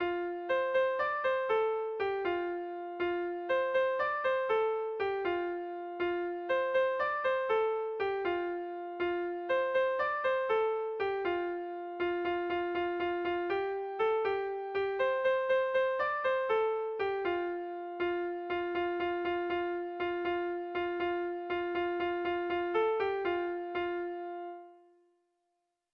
Irrizkoa
AABA